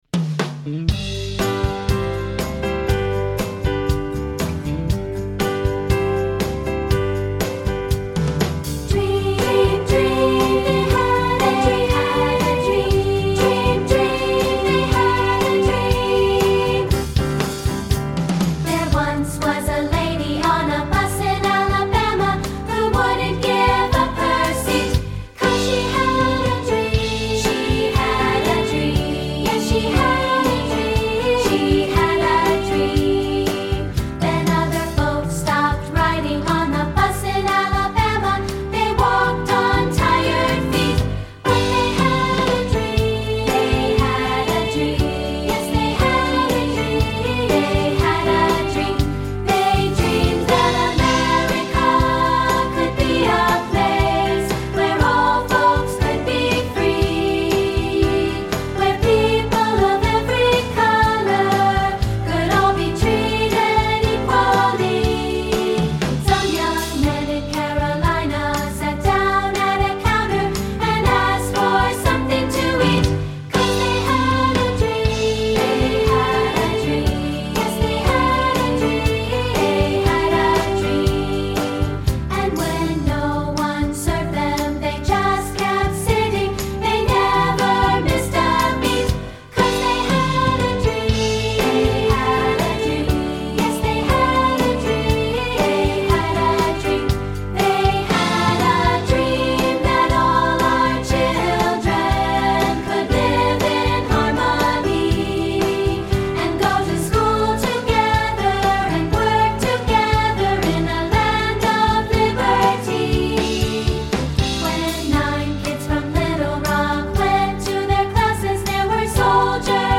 General Music Elementary Choral & Vocal Patriotic Choral